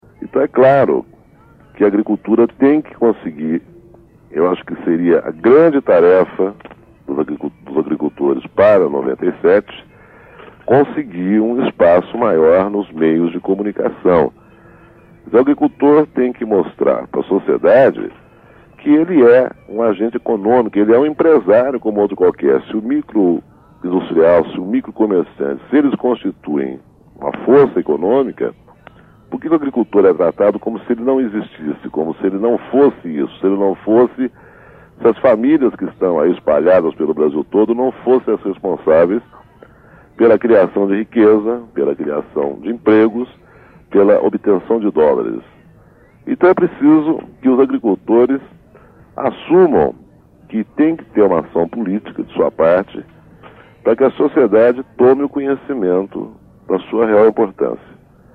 Aloysio Biondi disse neste trecho da carta falada para o programa A Voz da Contag, produzido pela Oboré, em 1996, que a grande tarefa dos (...)